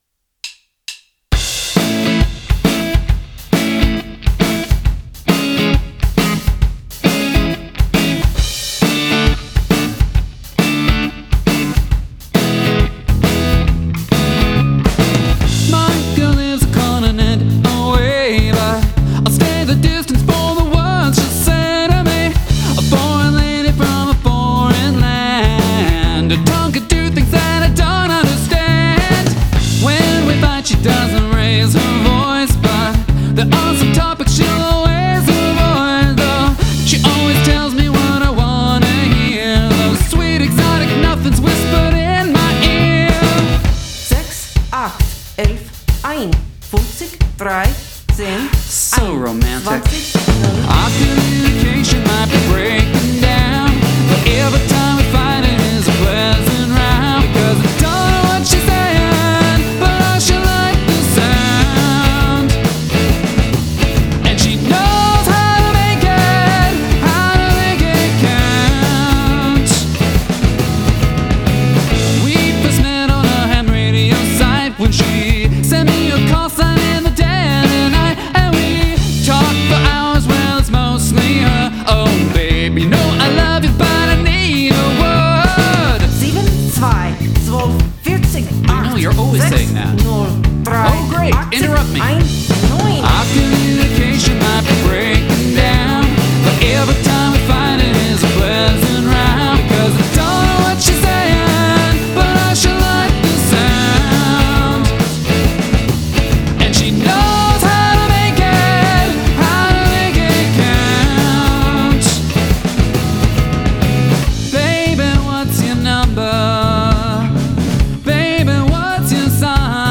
Must include a guest singing or speaking in another language